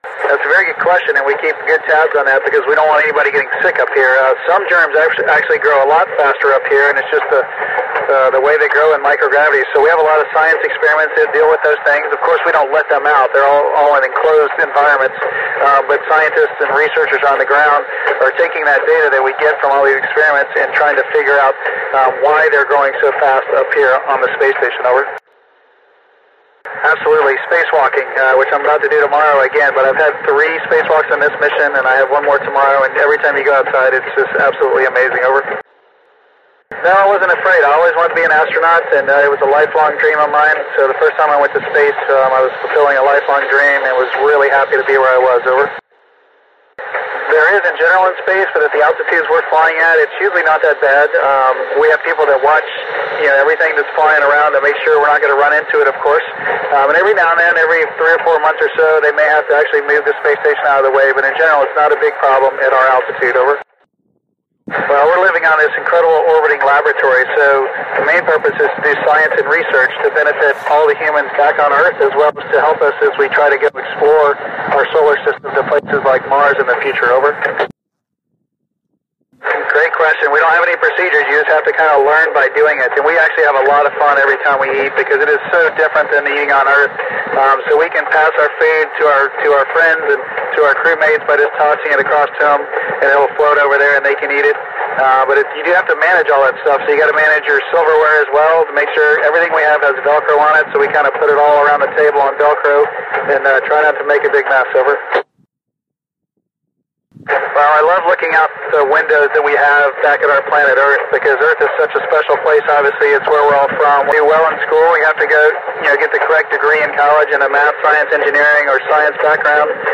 Record of the School Contact between Shane Kimbrough and the Council of State Science Supervisors, Los Angeles at Wed. 2017-03-29 18:03:38 UTC
We had a really perfect record of this School Contact from Berlin Germany, because it was managed via the Italian groundstation IK1SLD.
We were on a small hill in Berlin and used a hand-guided circular polarised X-Quad antenna and a yaesu FT857.
In the audiofile you can hear Shanes answers to the pupils questions No 4 to 20 listed below.